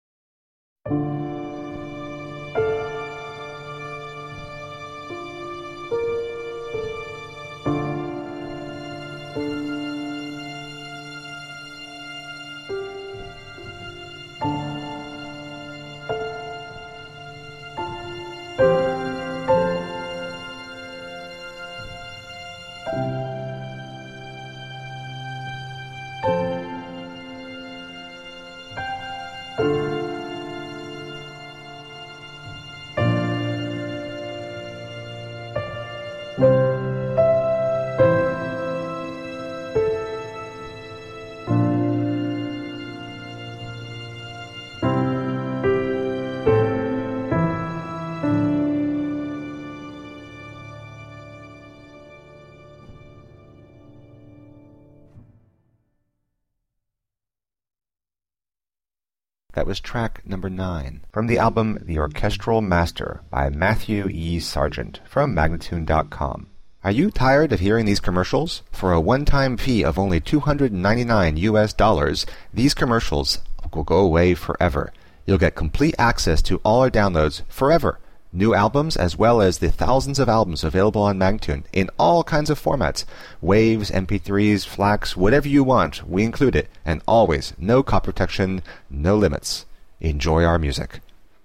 Masterfully composed orchestral and electronic film scores.